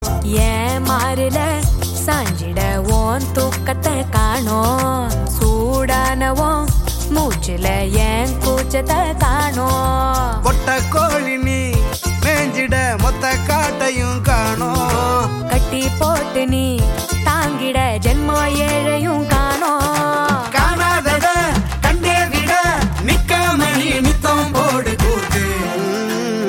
Category: Tamil Ringtones